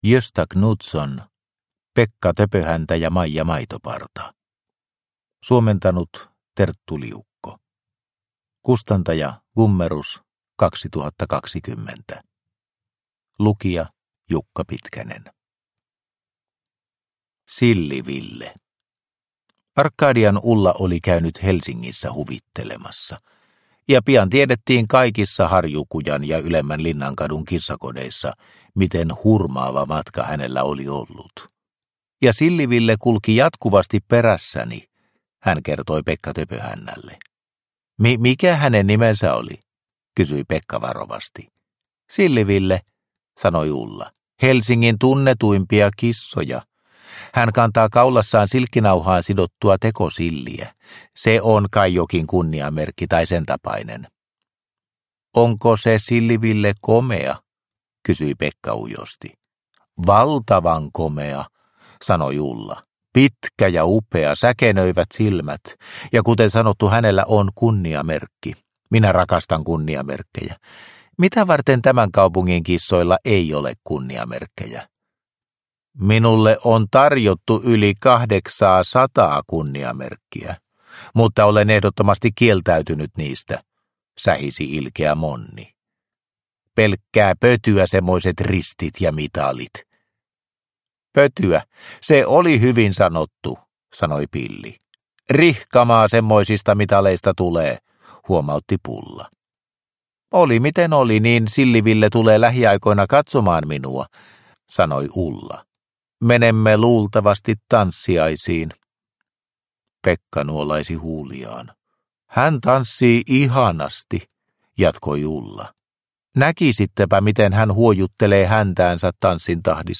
Pekka Töpöhäntä ja Maija Maitoparta – Ljudbok – Laddas ner